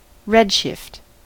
redshift: Wikimedia Commons US English Pronunciations
En-us-redshift.WAV